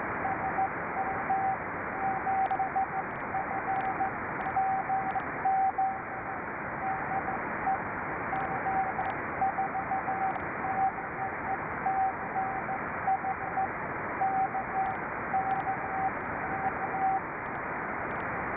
Echoes from some strong stations and from my station.